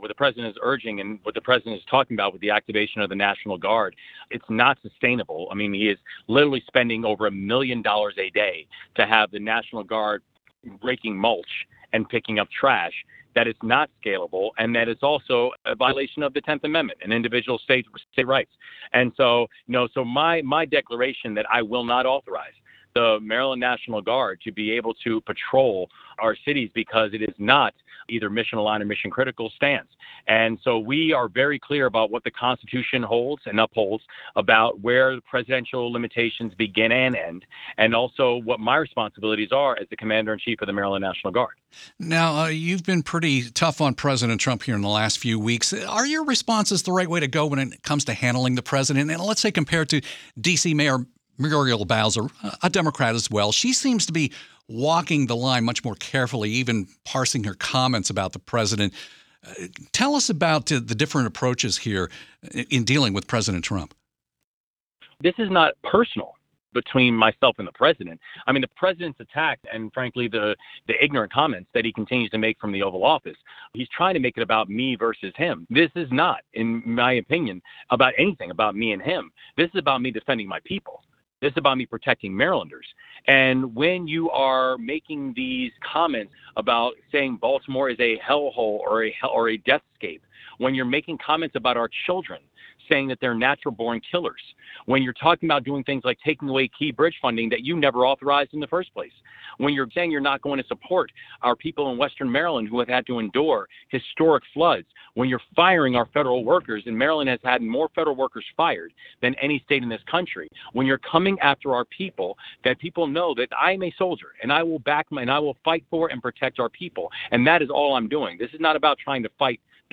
Maryland Gov. Wes Moore joined WTOP to discuss President Trump's latest threats to deploy the National Guard in Baltimore.